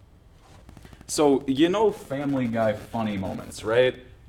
Fast and repetitive popping sound randomly interspersed throughout audio
Laptop sitting on table with USB microphone plugged into laptop, speaker standing 1-2 feet away.
Within the audio of a recorded video of normal speech, there are random sets of very fast, somewhat quiet popping noises, (they sound like a drum pad being hit over and over at about 700bpm), and I don’t know their source, how to remove them post-recording, or how to prevent their creation upon further recordings.
Also in the sample, another issue comes up near the end, where the audio gets all glitchy, but that’s the only time that happens in the recording, so it’s not as big a deal as the popping.